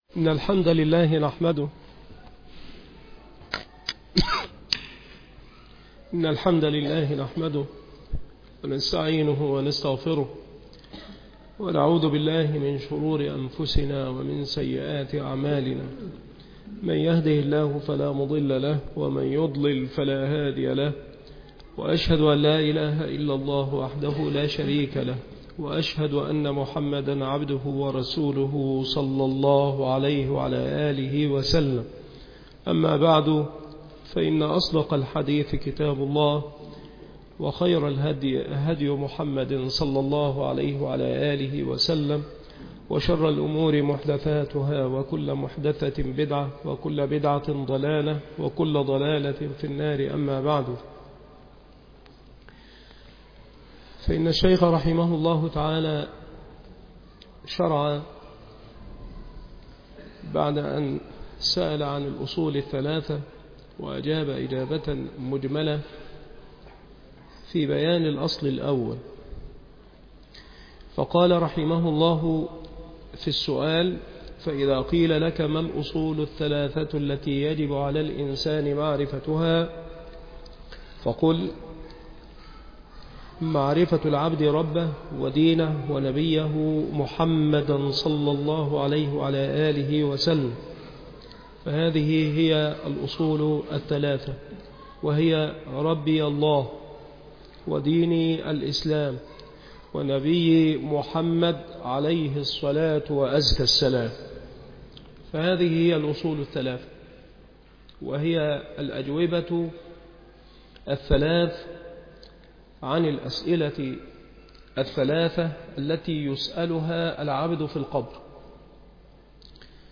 شرح ثلاثة الأصول وأدلتها